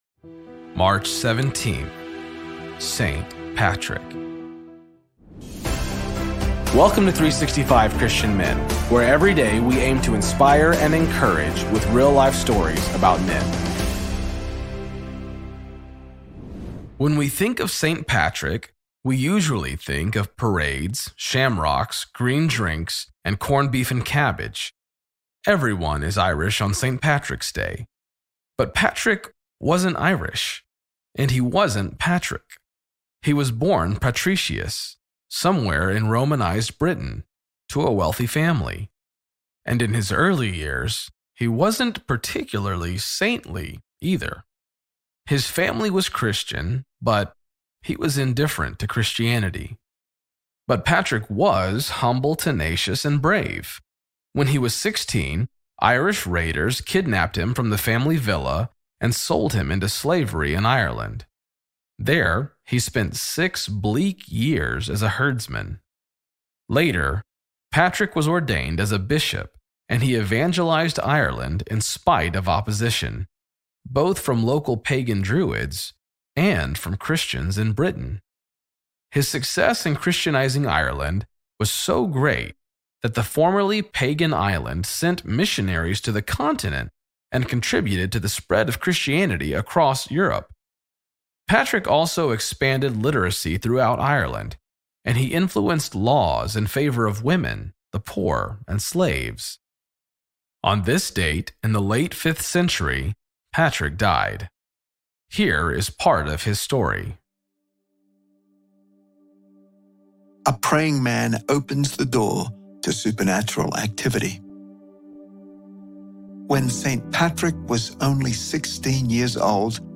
Story